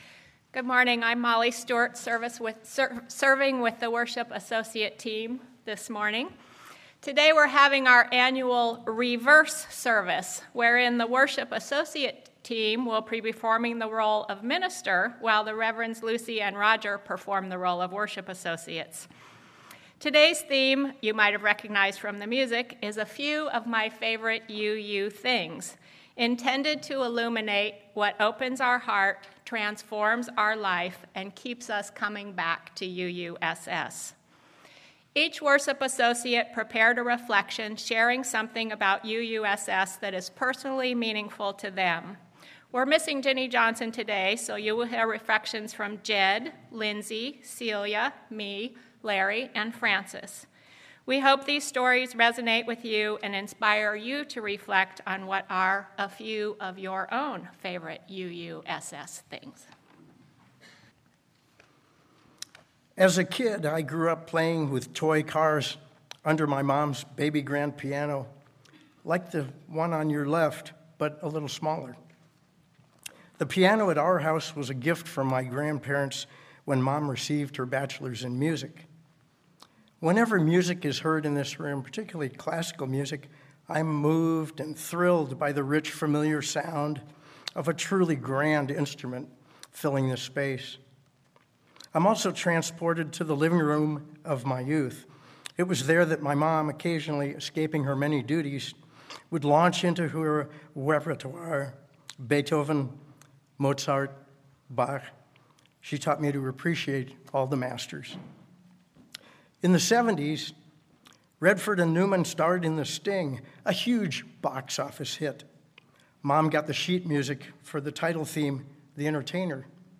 This Sunday members of the Religious Services Committee will each reflect on their favorite things about UUSS: what has opened their hearts, what keeps them coming back, how their lives have been enriched, and why they have committed their time, talents and support to our community.